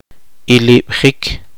[ʔi.liɓ.’χikʰ] verbo intransitivo